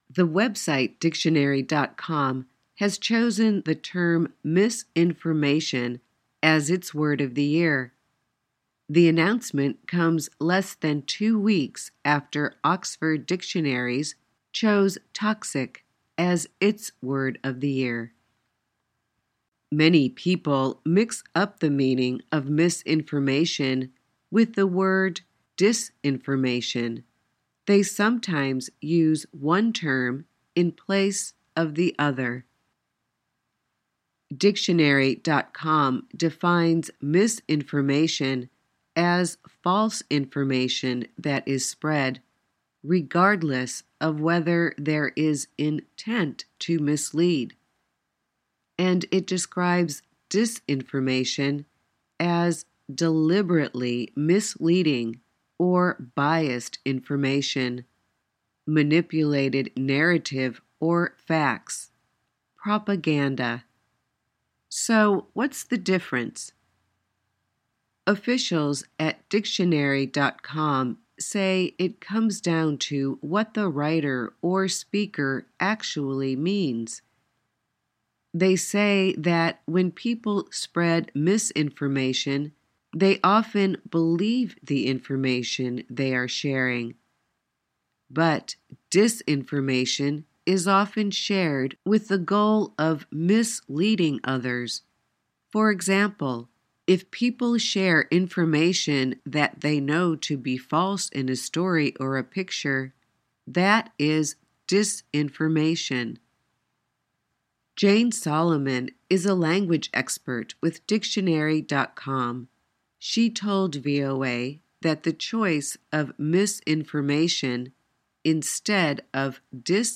慢速英语:错误信息或虚假信息